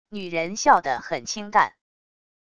女人笑的很清淡wav音频